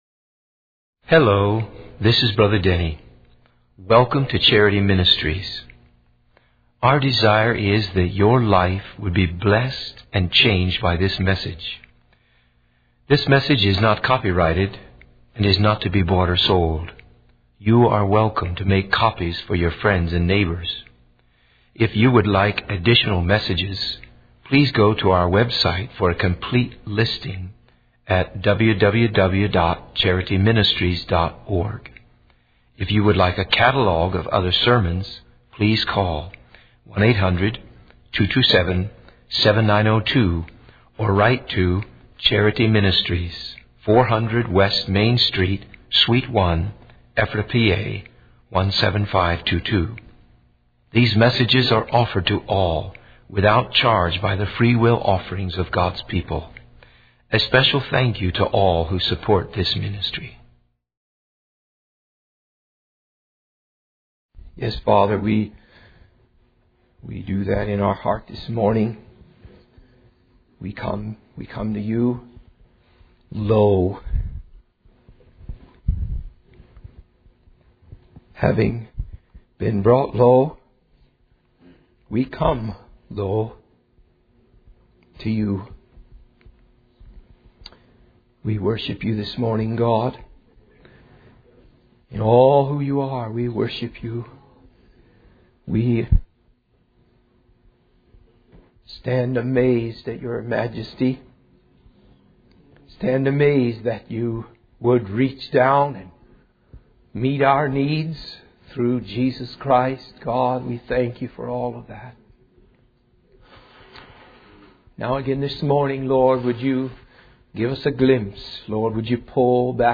In this sermon, the preacher emphasizes the humility of God, highlighting how the king of the kingdom is the greatest slave among them.